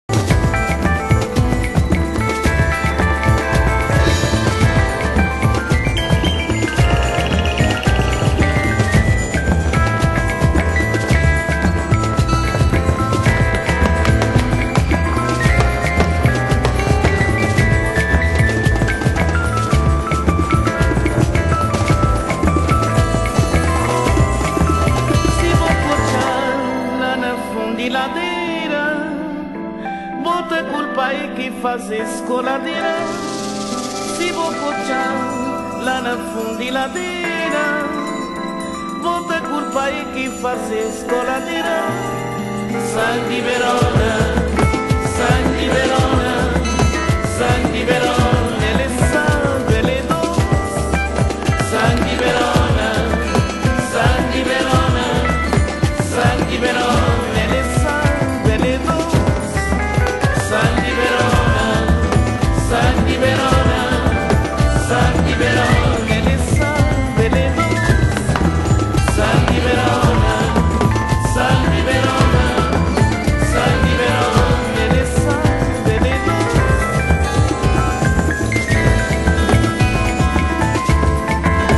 (Body & Soul Vocal) 　C1